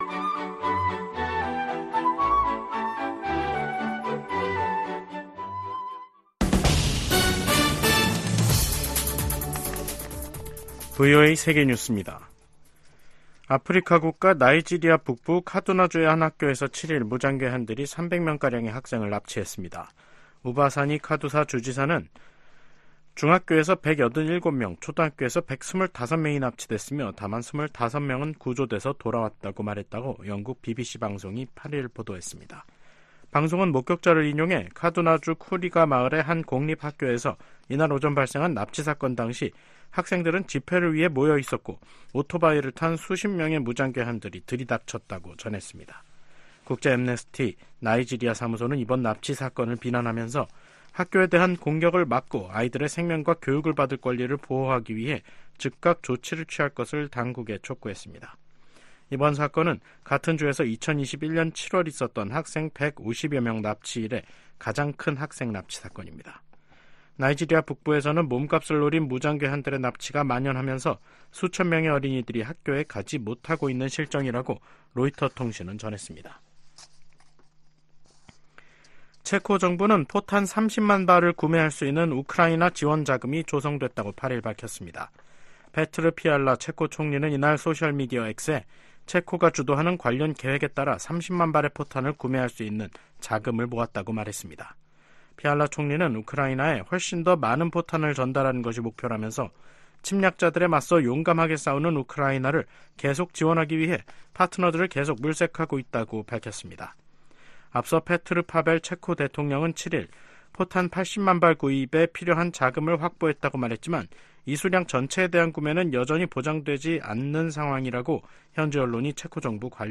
VOA 한국어 간판 뉴스 프로그램 '뉴스 투데이', 2024년 3월 8일 3부 방송입니다. 조 바이든 미국 대통령이 국정연설에서 자유 세계를 지키기 위해 우크라이나를 침공한 러시아를 막아야 한다고 강조했습니다. '프리덤실드' 미한 연합훈련이 진행 중인 가운데 김정은 북한 국무위원장이 서울 겨냥 포사격 훈련을 지도했습니다.